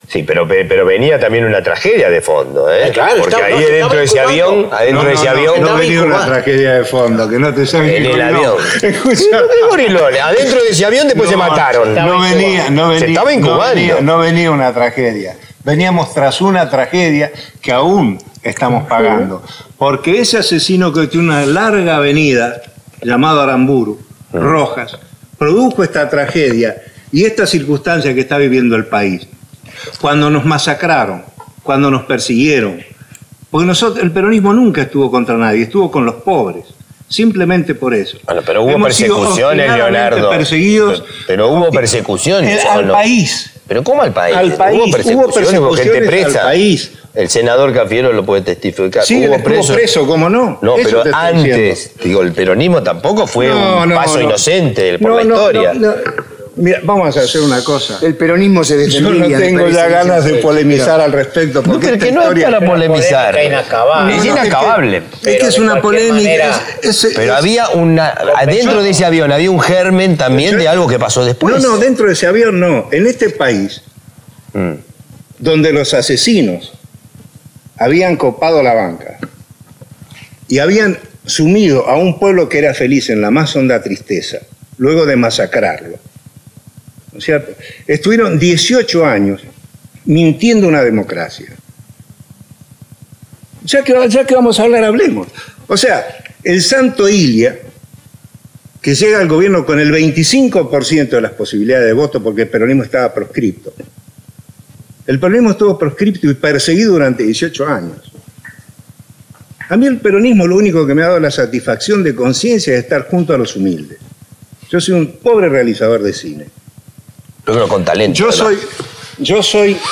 Para cerrar, vale recordar un audio de Leonardo Favio de 1995, en el que defendía con pasión lo mejor del peronismo.